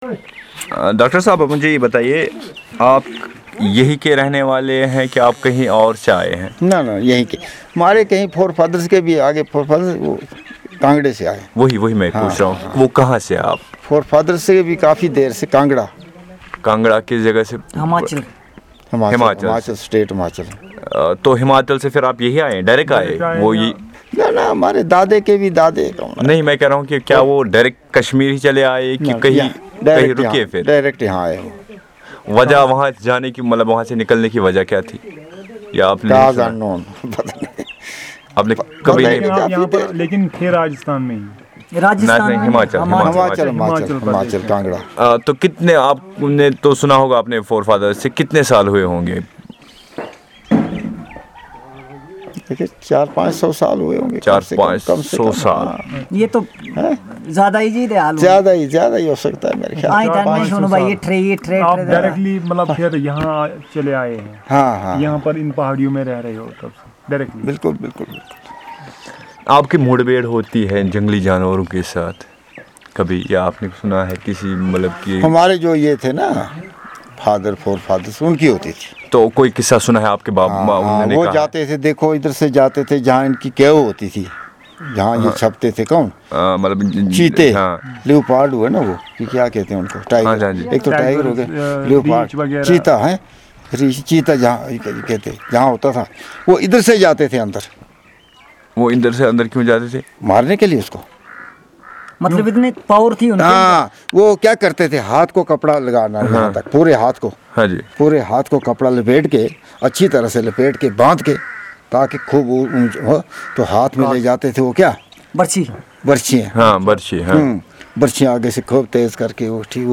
NotesThis is an oral narrative on the stories of hunting wild animals .